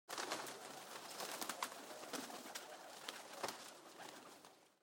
دانلود آهنگ پرنده 26 از افکت صوتی انسان و موجودات زنده
دانلود صدای پرنده 26 از ساعد نیوز با لینک مستقیم و کیفیت بالا
جلوه های صوتی